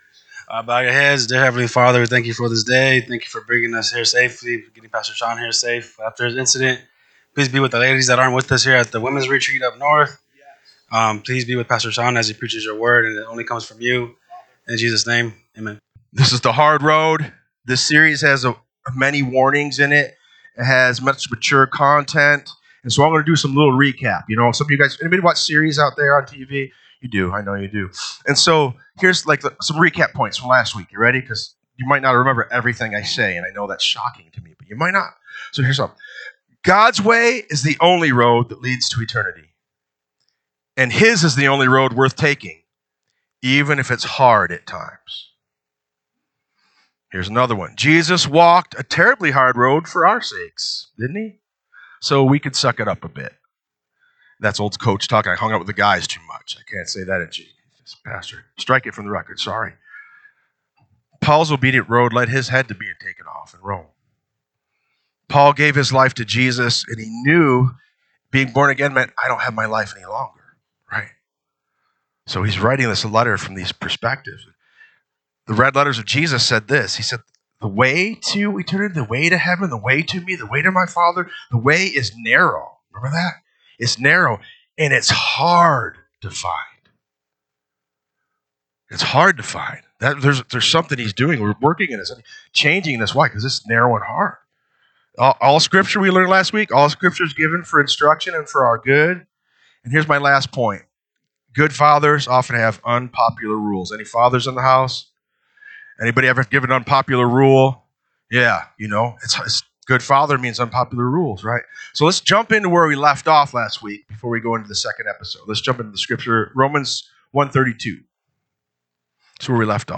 NSCF Sermons Online The Hard Road - Heart Check - Ch. 2 Apr 20 2026 | 00:33:22 1x 00:00 / 00:33:22 Subscribe Share RSS Feed Share Link Embed